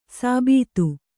♪ sābītu